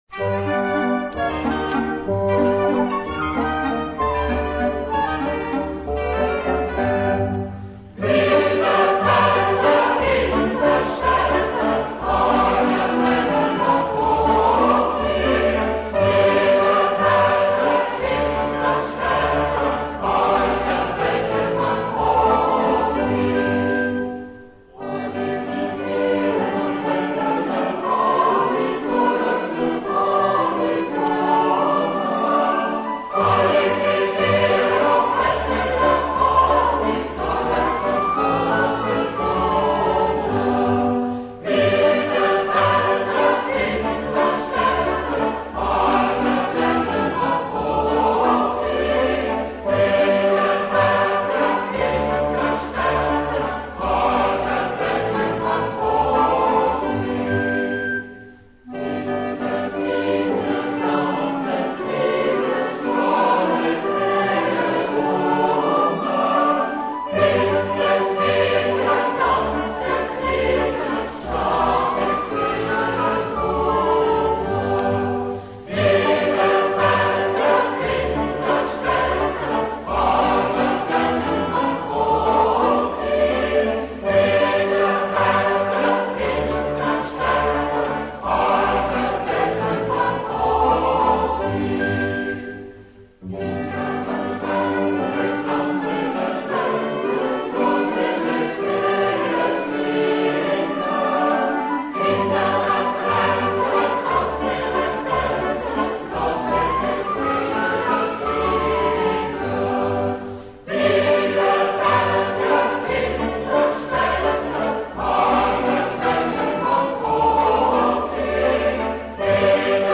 Genre-Style-Forme : Populaire ; Chanson ; Danse ; Profane ; Valse
Caractère de la pièce : rapide
Type de choeur : SATB  (4 voix mixtes )
Tonalité : sol majeur